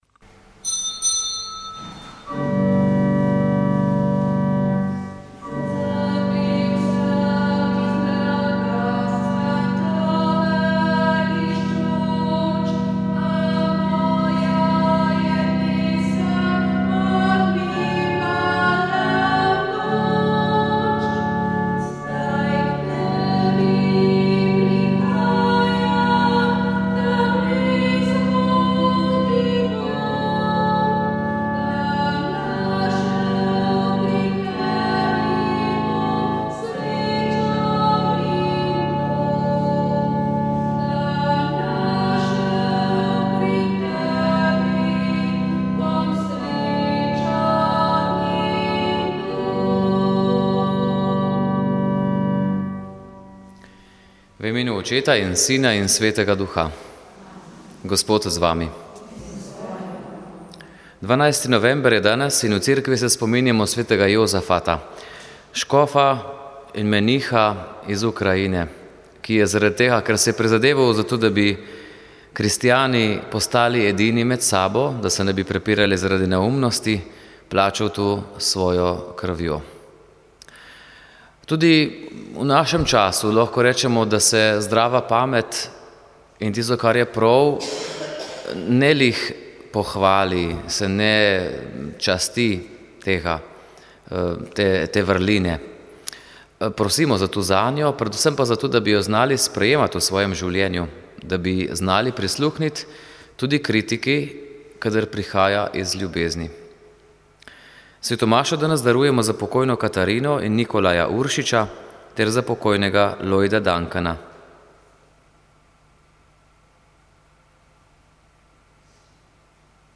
Sv. maša iz župnijske cerkve sv. Jožefa in sv. Barbare iz Idrije 4. 11.
pel pa župnijski pevski zbor sv. Urbana iz Godoviča.